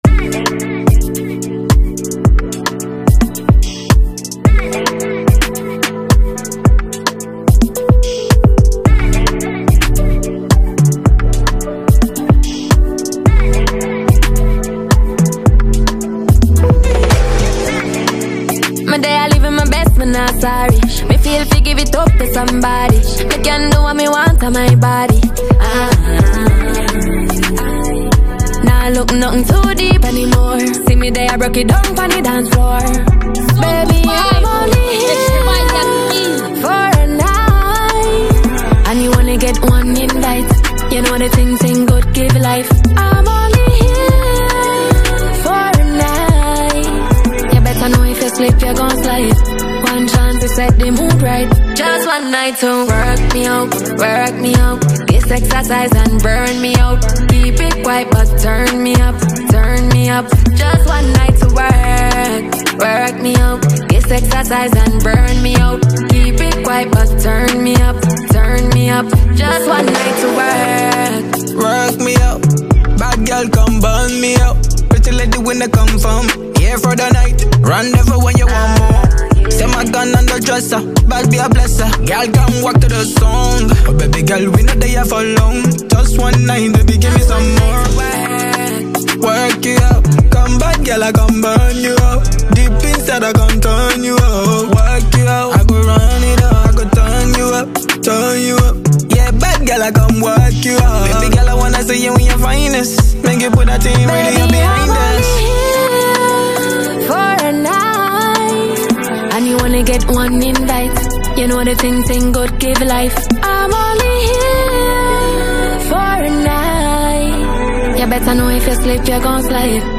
Genero: Afro Beat